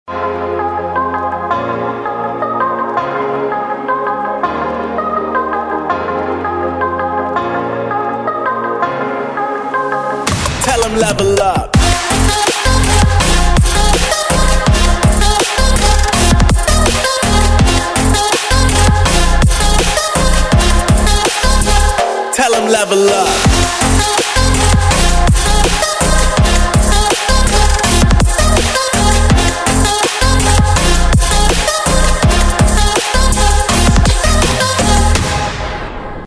Категория: Trap рингтоны